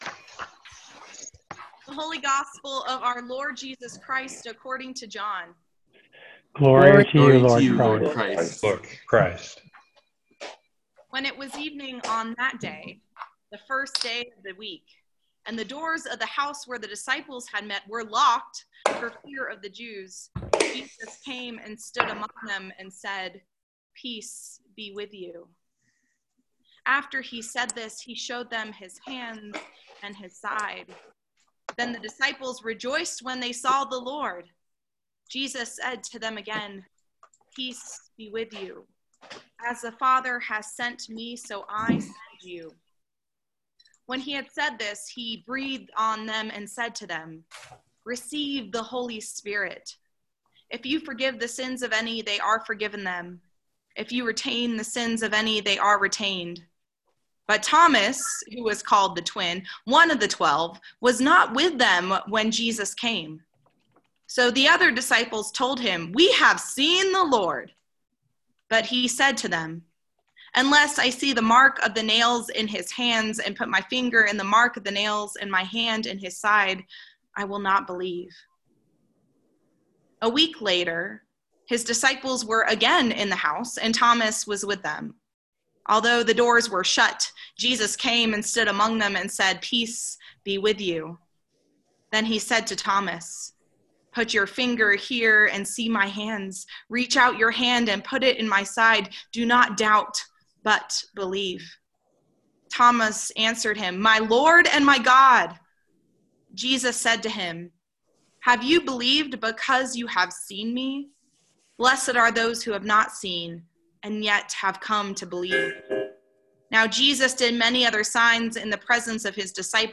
A sermon for the 2nd Sunday of Easter, Year A. Both audio and video are available.